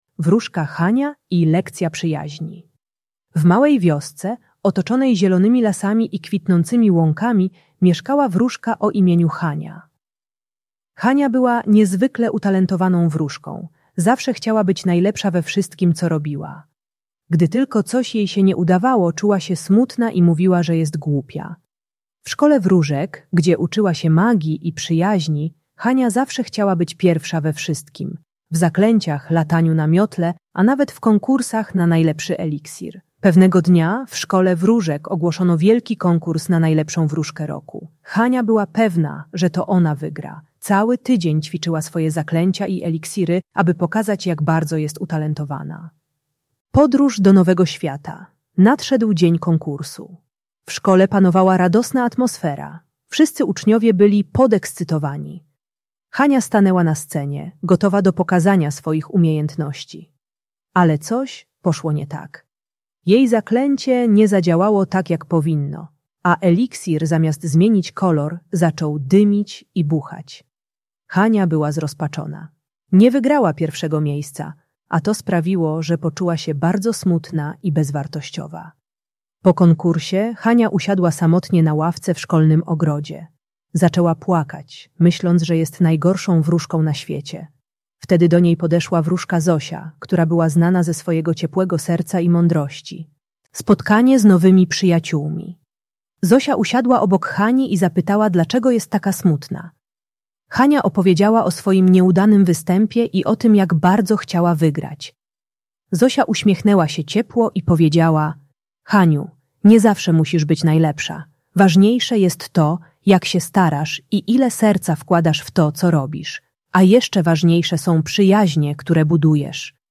Historia wróżki Hani - Przedszkole | Audiobajka